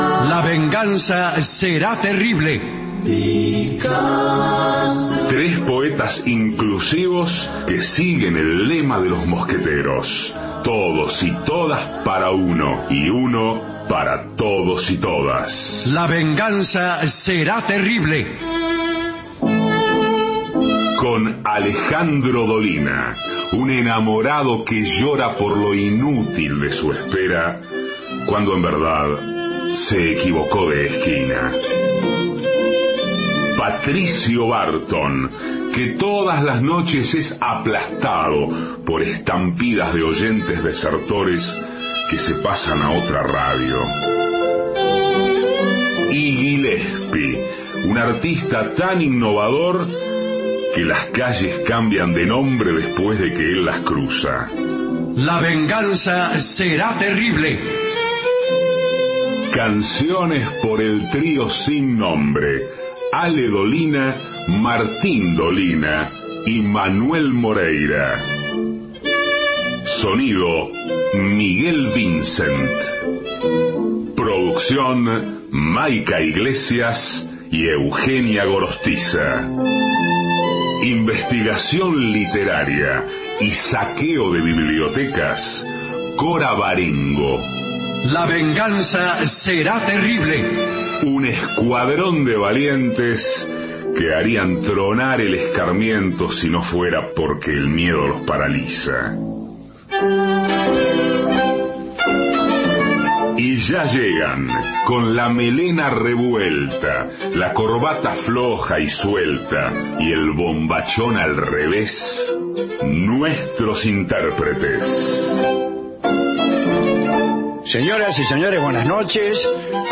Estudios Radio AM 750 Alejandro Dolina